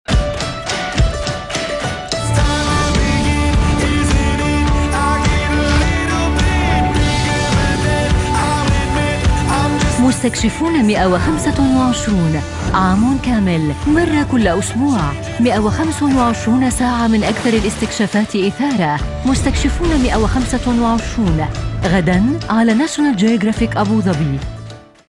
Sprechprobe: Sonstiges (Muttersprache):
If you're business requires an arabic female voice talent for recordings on a one time only or regular frequency, feel free to contact me.